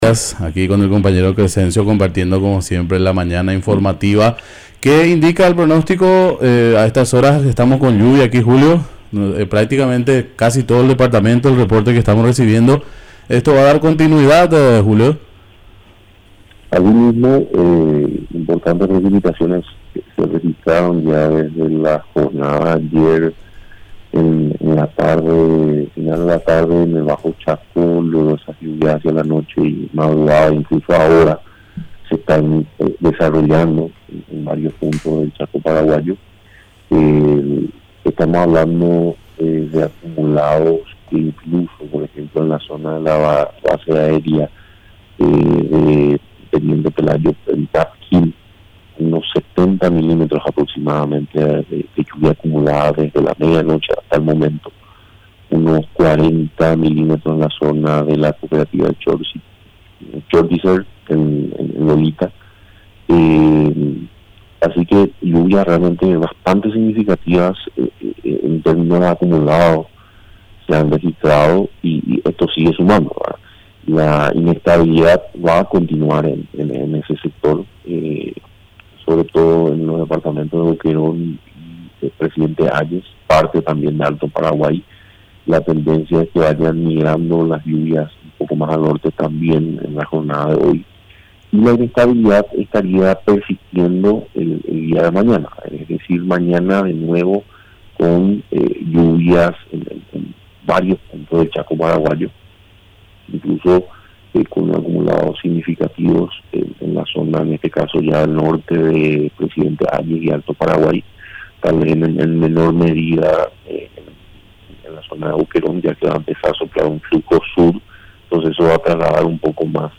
Entrevistas / Matinal 610 Informe meteorológico e hidrológico Nov 18 2024 | 00:10:42 1x 00:00 / 00:10:42 Subscribe Share RSS Feed Share Link Embed